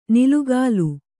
♪ nilugālu